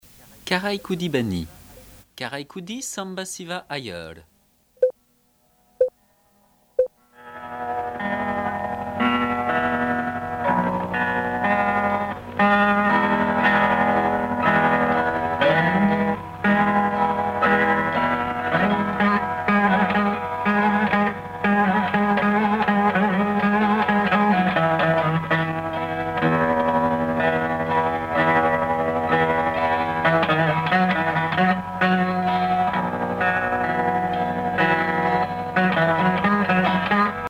Musique carnatique